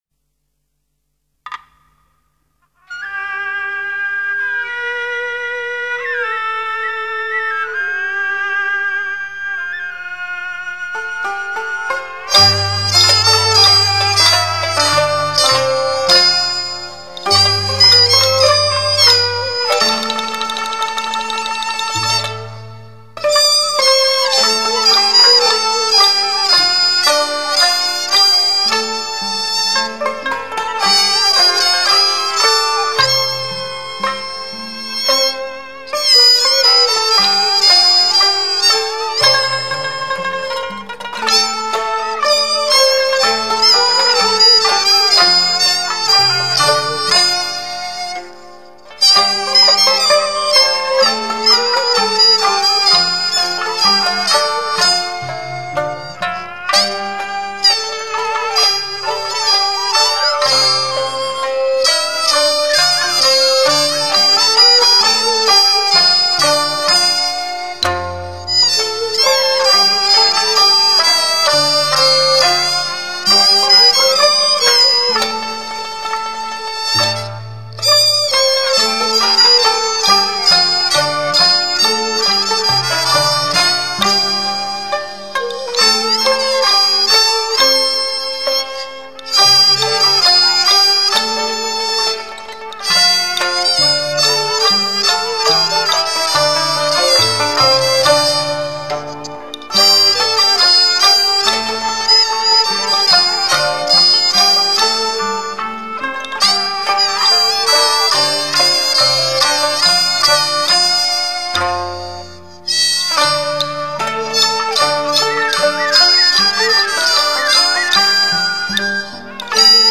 旋律优美  源远流长  古调新韵  风雅浓郁
是古朴典雅、优美抒情。它的主要乐器是二弦、二胡、扬琴以及锣鼓等打击乐器 。